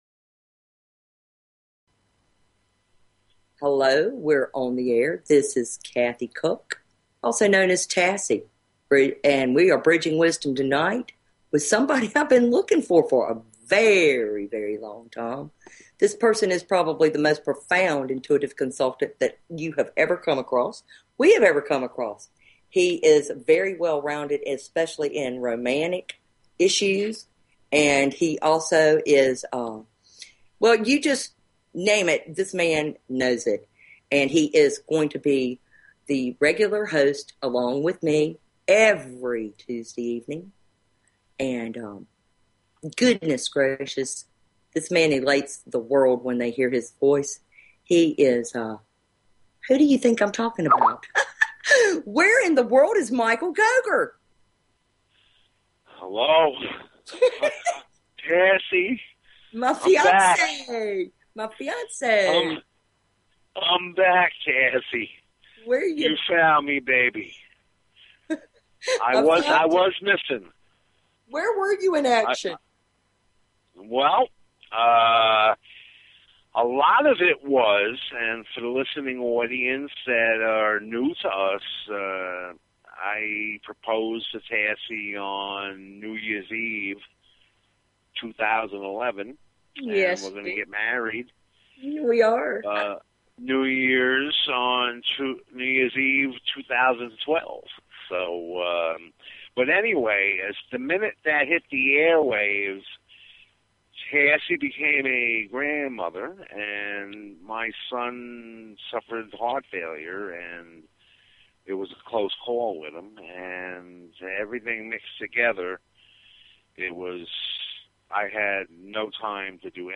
Talk Show Episode, Audio Podcast, Bridging_Wisdom and Courtesy of BBS Radio on , show guests , about , categorized as